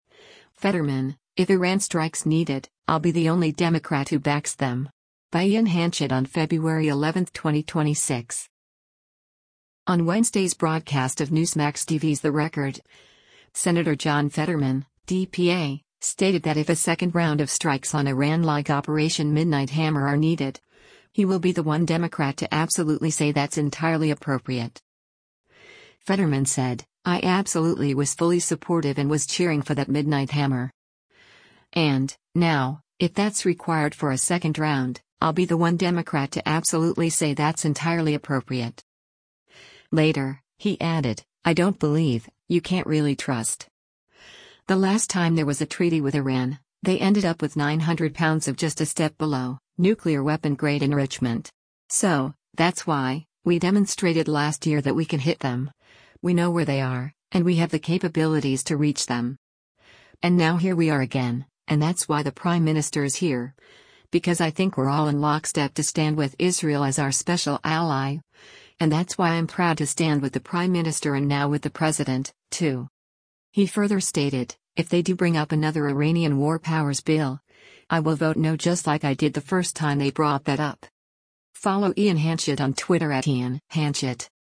On Wednesday’s broadcast of Newsmax TV’s “The Record,” Sen. John Fetterman (D-PA) stated that if a second round of strikes on Iran like Operation Midnight Hammer are needed, he will “be the one Democrat to absolutely say that’s entirely appropriate.”